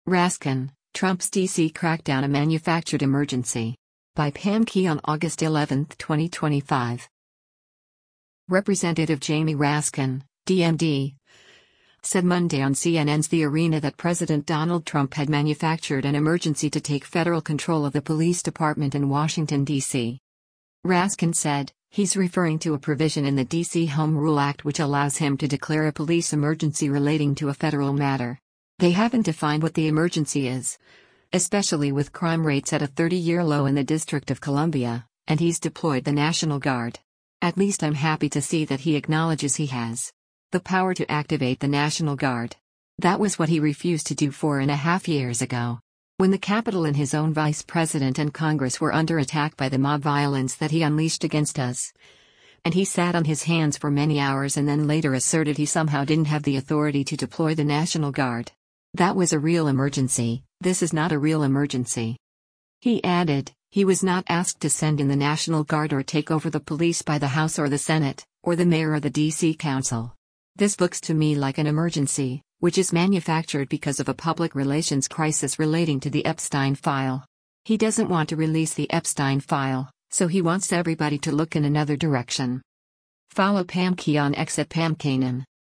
Representative Jamie Raskin (D-MD) said Monday on CNN’s “The Arena” that President Donald Trump had “manufactured” an emergency to take federal control of the police department in Washington, D.C.